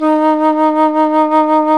Index of /90_sSampleCDs/Roland LCDP04 Orchestral Winds/FLT_Alto Flute/FLT_A.Flt vib 2
FLT ALTOFL07.wav